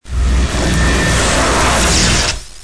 Молнии: